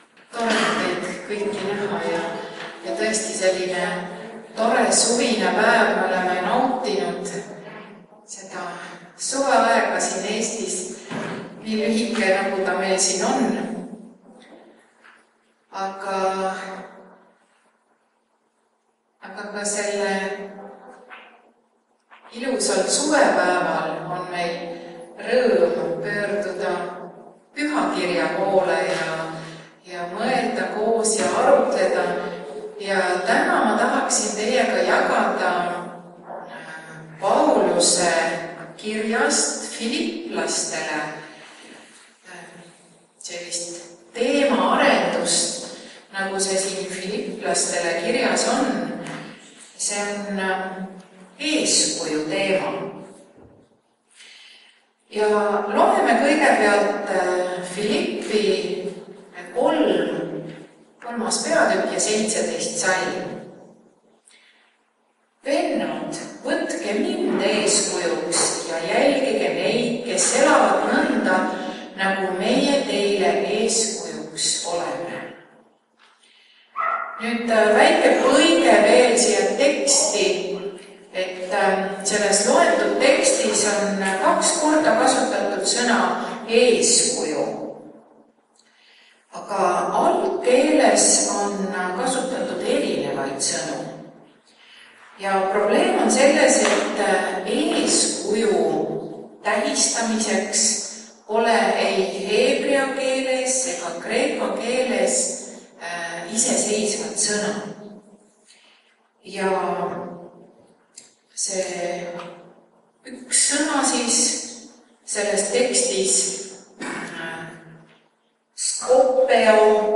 Tänane jutlus on teemal PAULUS FILIPI KOGUDUSES Tänase jutluse salvestis sai teoks videokaamera abil kuna jumalateenistust salvestav arvuti läks koosoleku alguseks katki.
Jutlused